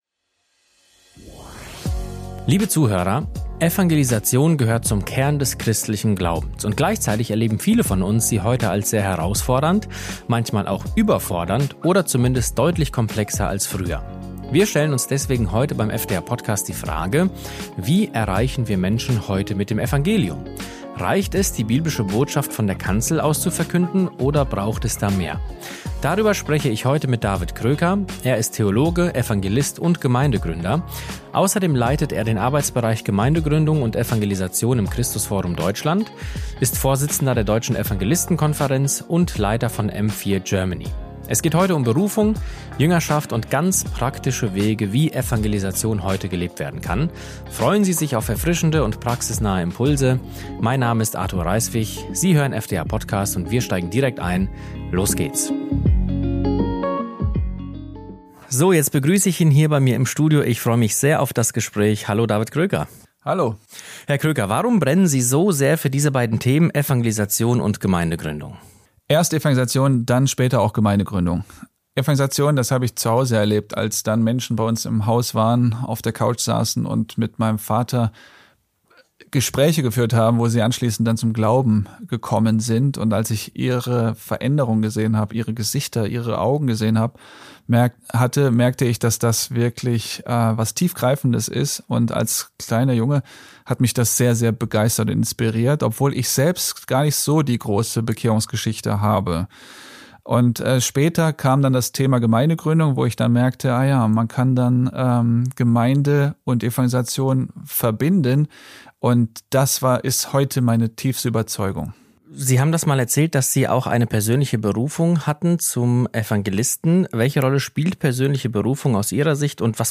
Im Gespräch geht es um persönliche Berufung, die zentrale Rolle von Jüngerschaft und um ganz praktische Wege, wie Evangelisation heute gelebt werden kann – auch dort, wo es noch keine fertigen Strukturen oder Gemeinden gibt.